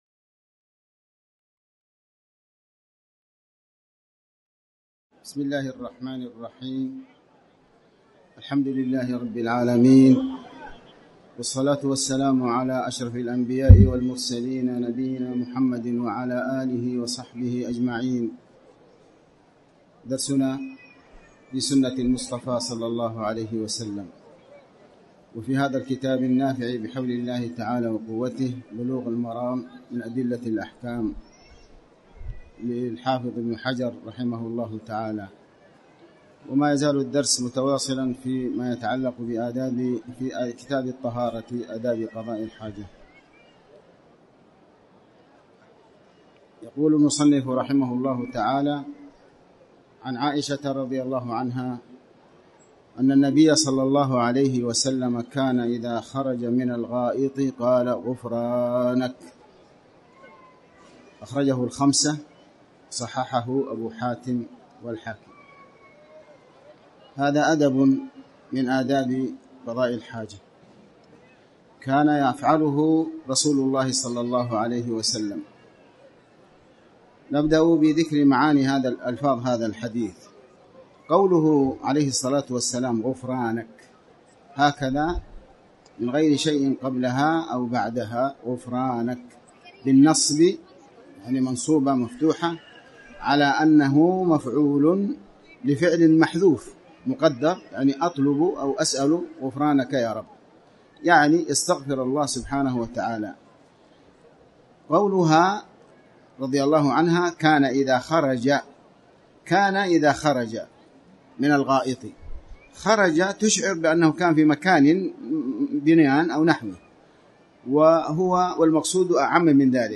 تاريخ النشر ١١ ذو القعدة ١٤٣٨ هـ المكان: المسجد الحرام الشيخ